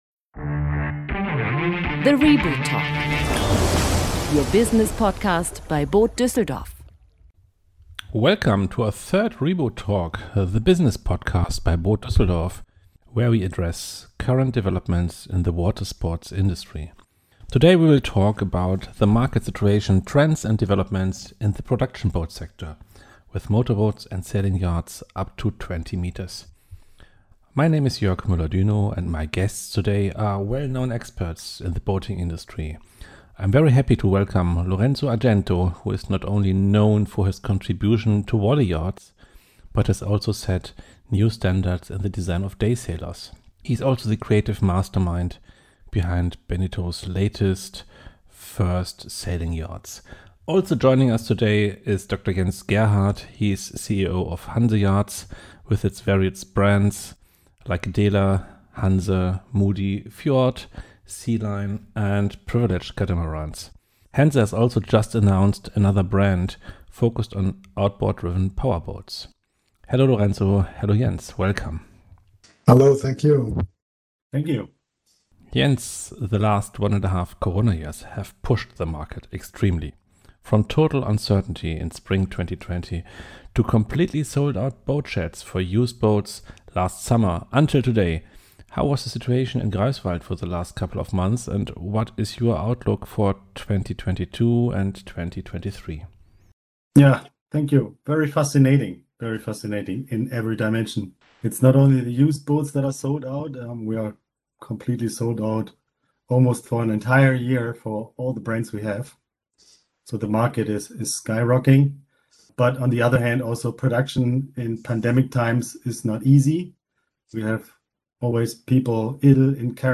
Together with experts of the industry, we look back at this massively successful year 2020 for boat yards and brokers, discuss the development of 2021 and dare to look into the future.